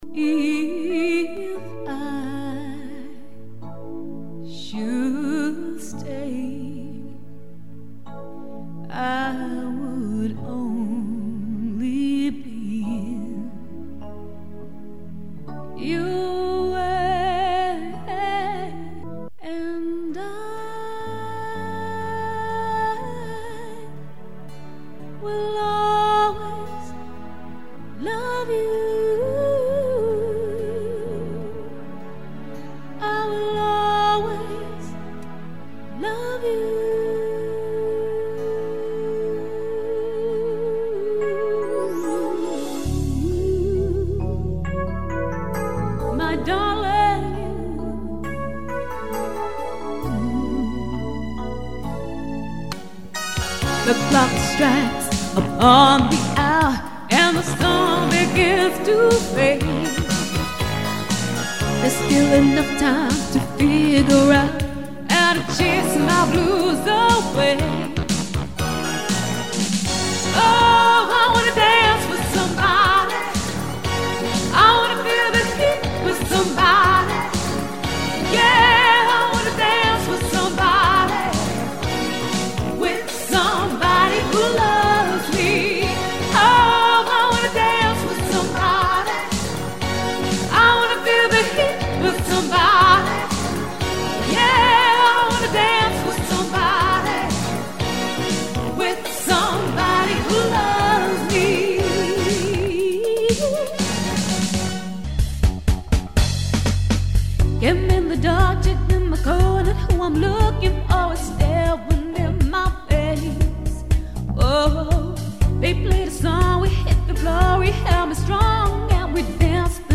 • Authentic & Professional Tribute